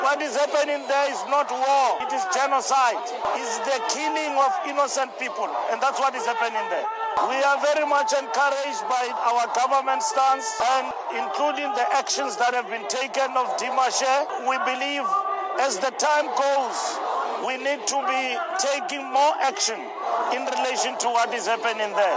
# Die sekretaris-generaal van die ANC, Fikile Mbalula, doen ‘n beroep vir ‘n onmiddellike skietstaking in Gaza en die hervatting van vredesonderhandelinge tussen Israel en Palestina. Mbalula het tydens die Palestynse solidariteitsopmars in Kaapstad gepraat.